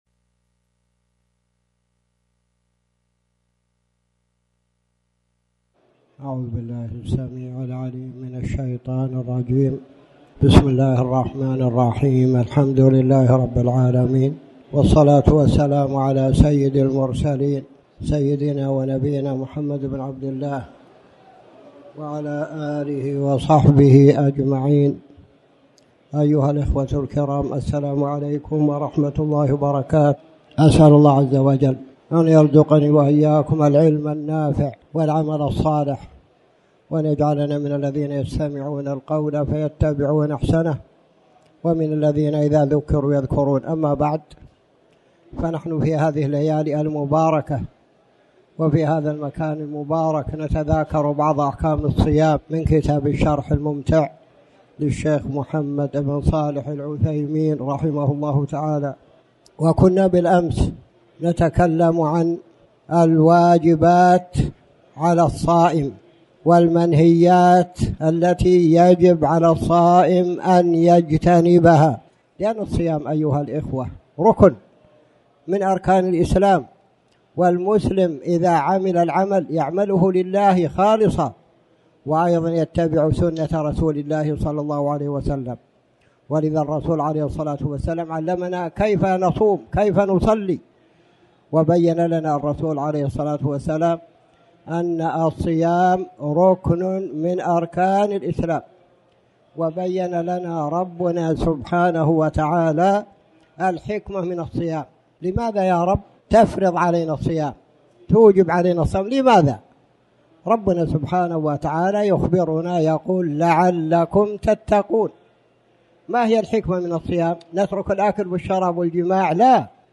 تاريخ النشر ٧ شعبان ١٤٣٩ هـ المكان: المسجد الحرام الشيخ